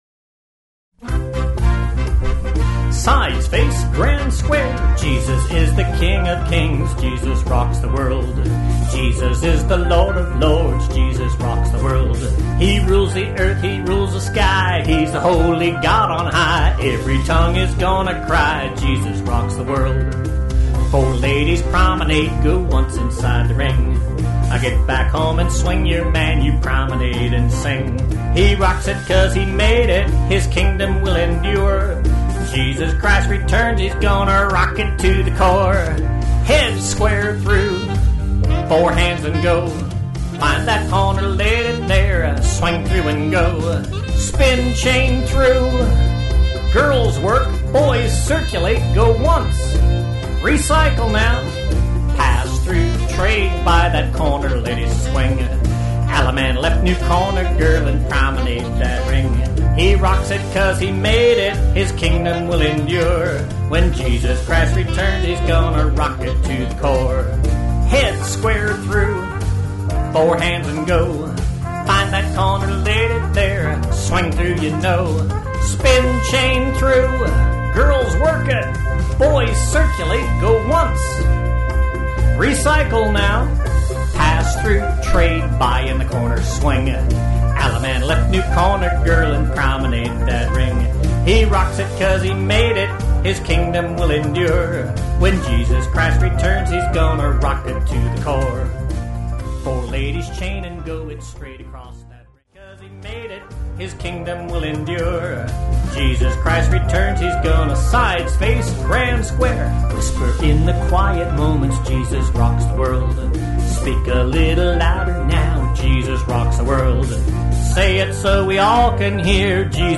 Spiritual Music